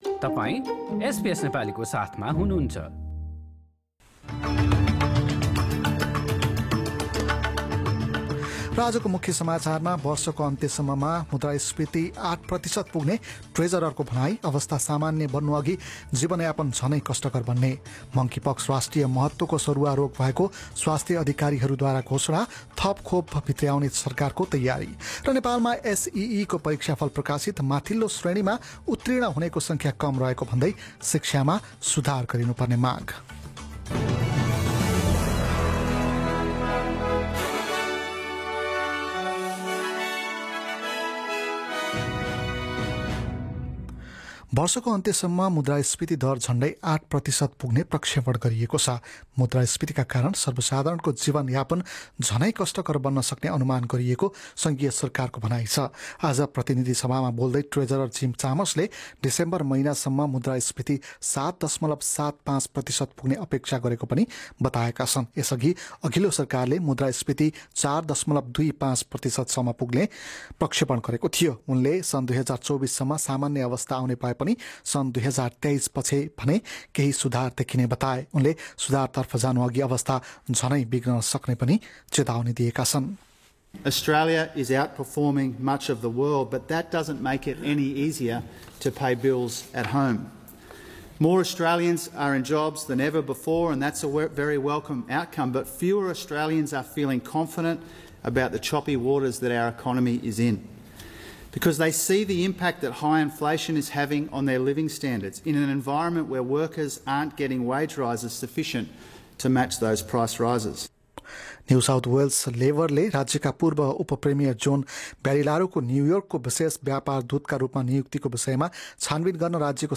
Listen to the latest news headlines from Australia in Nepali. In this bulletin, treasurer Jim Chalmers says inflation is expected to peak by the end of the year.